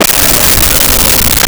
Creature Growl 05
Creature Growl 05.wav